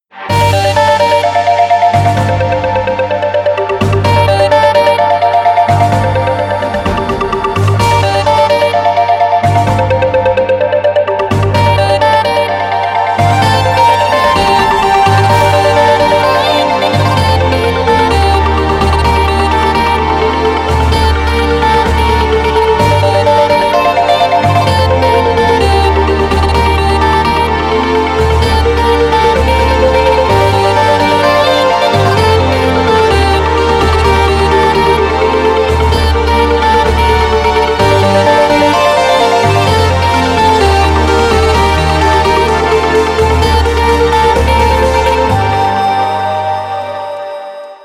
• Качество: 320, Stereo
без слов
club